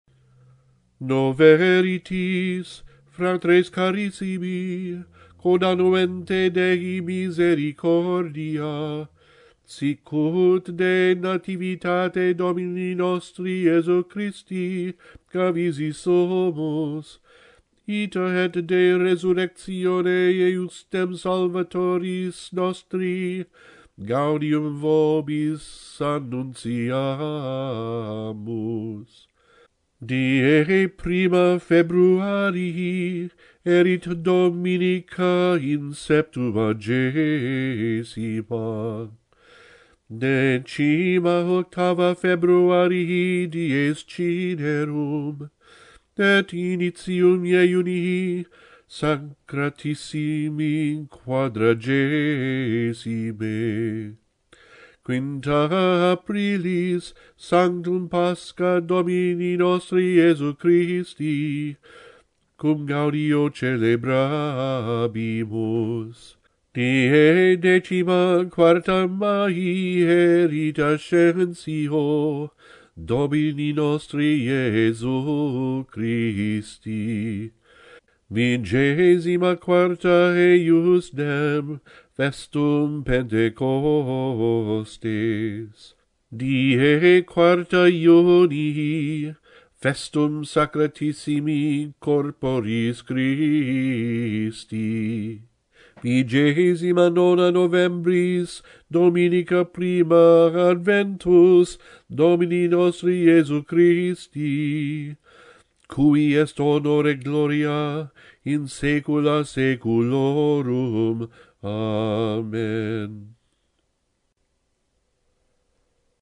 At Epiphany (real Epiphany is 6 January) we Latins have traditionally chanted a solemn proclamation of the key liturgical dates or movable feasts for the new year of salvation, just begun.